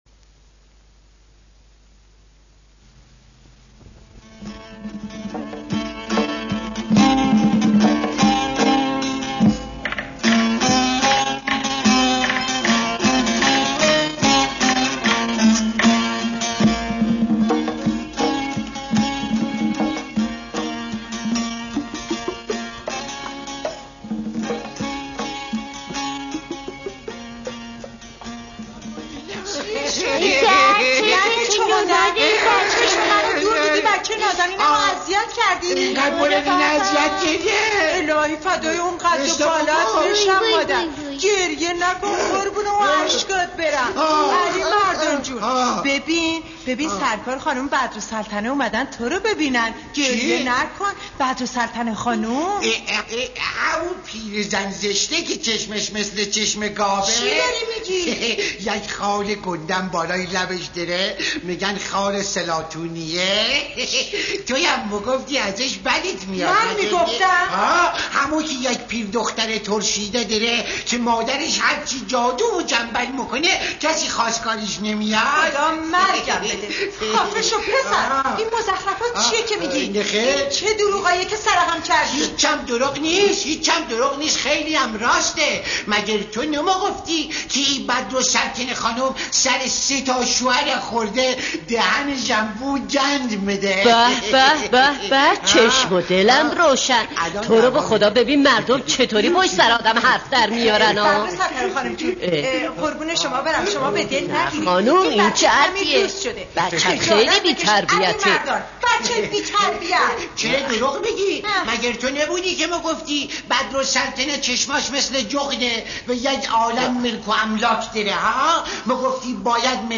قصه کودکانه صوتی علیمردان خان
قصه-کودکانه-صوتی-علیمردان-خان-بخش-دوم-1.mp3